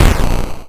bakuhatu27.wav